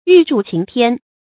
玉柱擎天 注音： ㄧㄩˋ ㄓㄨˋ ㄑㄧㄥˊ ㄊㄧㄢ 讀音讀法： 意思解釋： 謂棟梁之材可任天下大事。